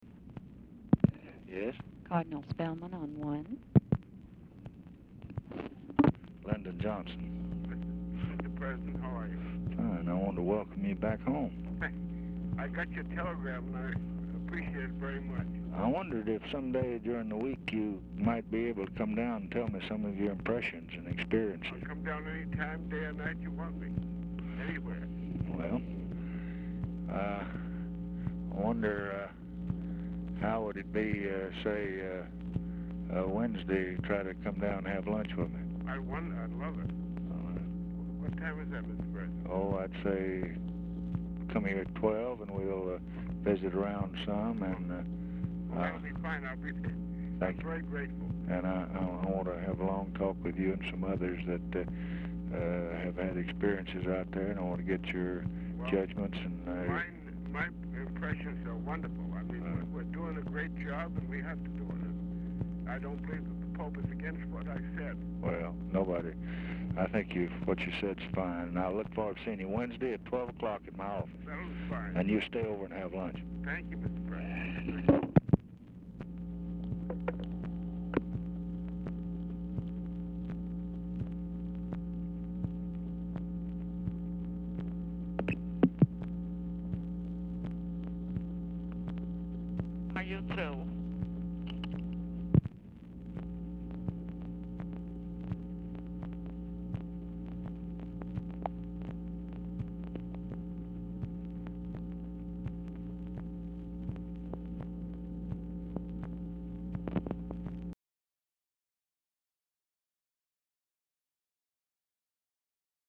Title Telephone conversation # 11359, sound recording, LBJ and FRANCIS SPELLMAN, 1/16/1967, 11:31AM Archivist General Note "SUMMARIZED"; TELEPHONE OPERATOR SAYS "ARE YOU THROUGH?" AT END OF RECORDING
Format Dictation belt
Location Of Speaker 1 Oval Office or unknown location
Specific Item Type Telephone conversation